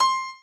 b_piano1_v100l4o7c.ogg